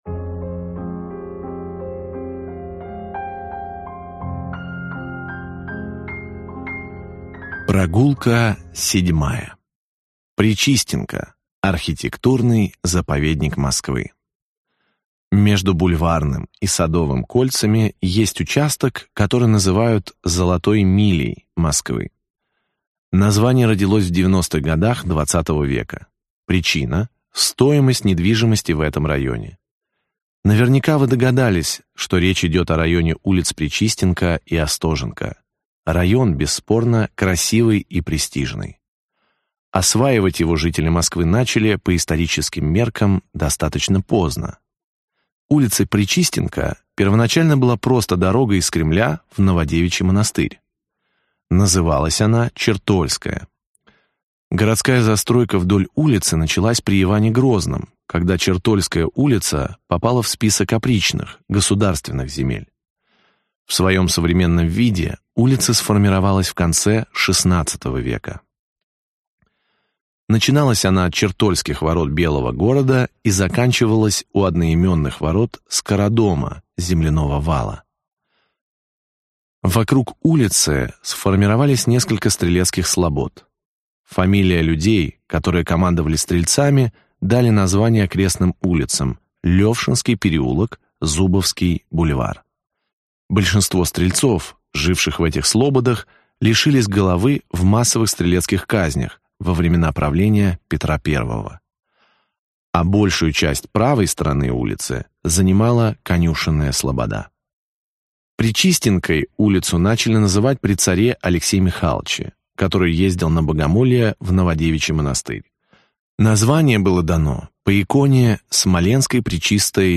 Аудиокнига 8 прогулок по Москве. Глава №7. Пречистенка – архитектурный заповедник Москвы | Библиотека аудиокниг